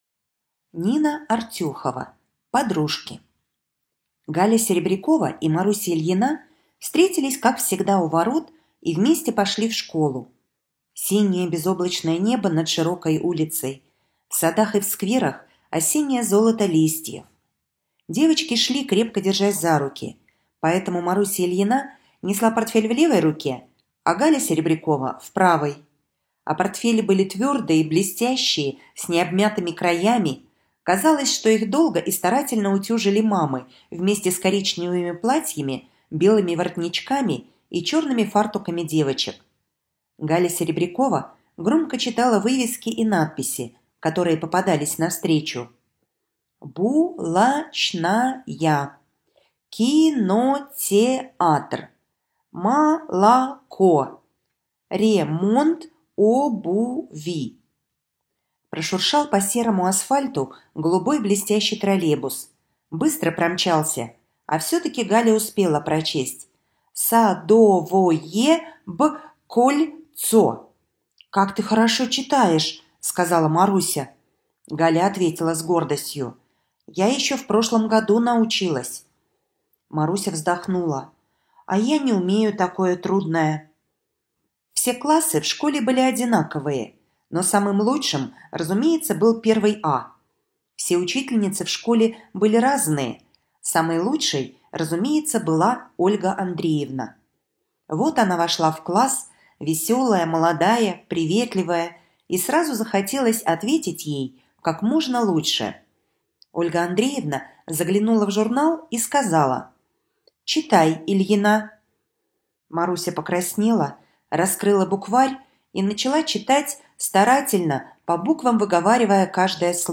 Аудиорассказ «Подружки»